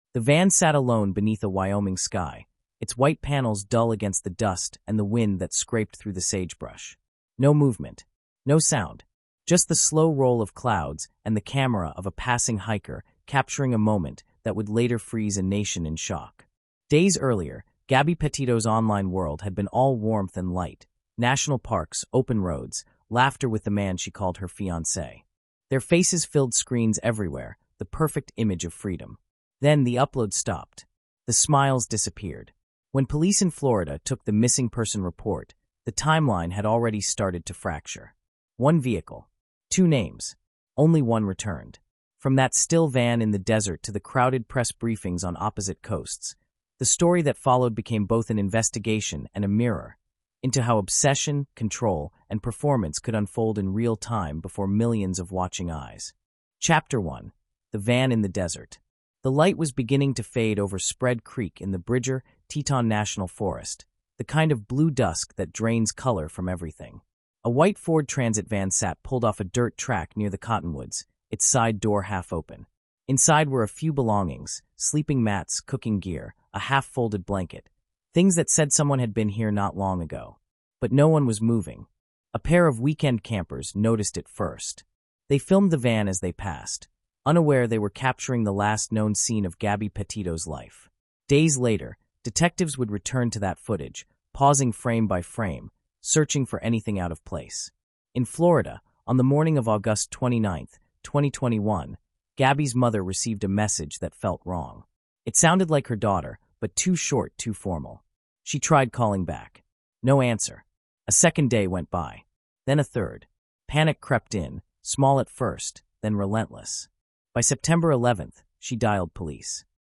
“Killing of Gabby Petito” is a three-chapter cinematic true-crime narrative that reconstructs one of the most haunting modern cases of love, control, and tragedy in the age of social media. Told with forensic accuracy and film-like pacing, it traces the young traveler’s disappearance across America — from joyful road-trip vlogs to a national manhunt that exposed the private collapse behind public perfection.